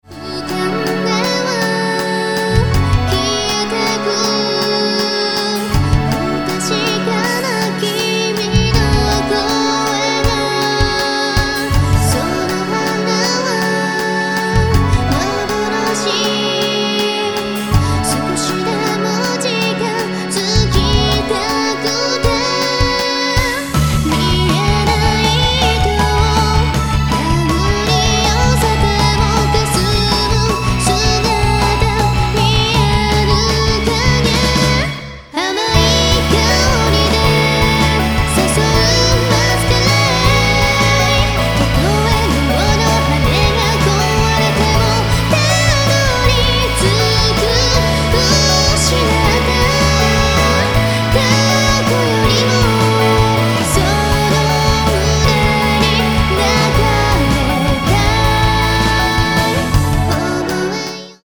内容：オリジナルボーカルアルバム